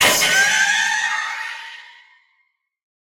latest / assets / minecraft / sounds / mob / allay / death1.ogg